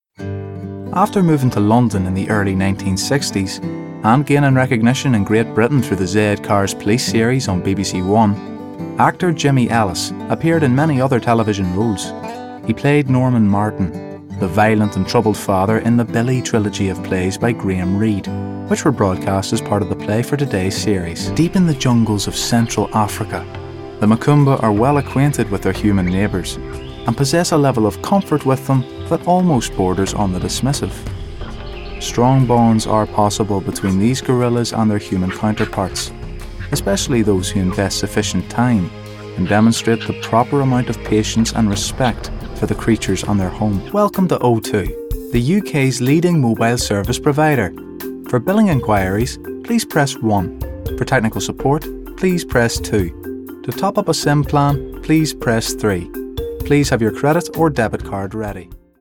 20s-30s. Male. Northern Irish.